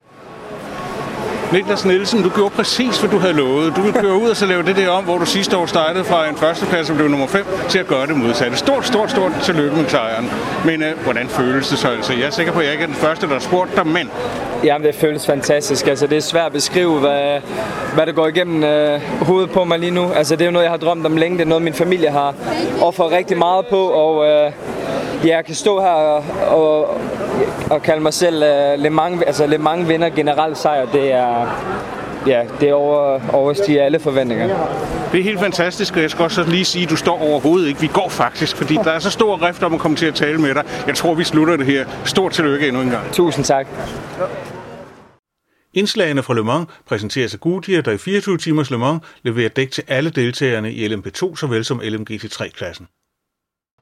Interview med Nicklas Nielsen efter Le Mans triumfen